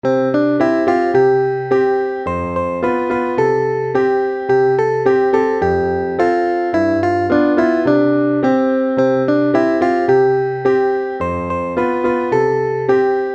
Partitura para piano, voz y guitarra.